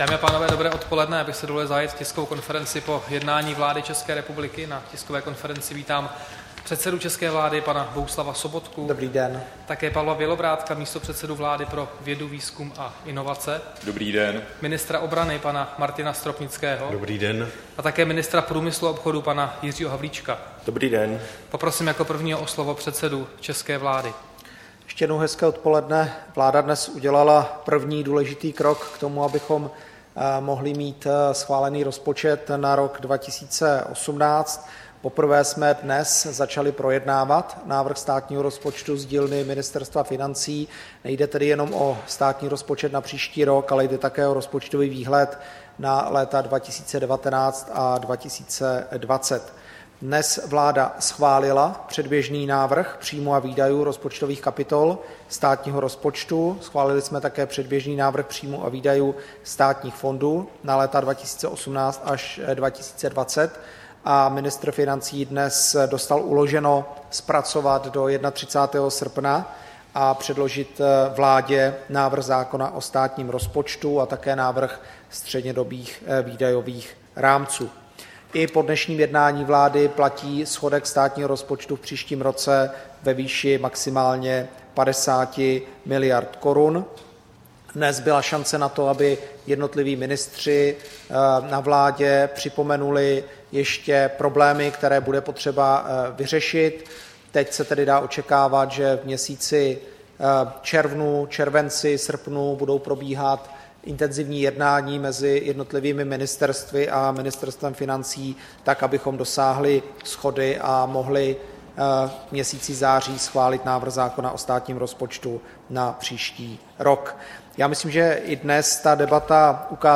Tisková konference po jednání vlády, 14. června 2017